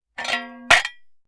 Metal_52.wav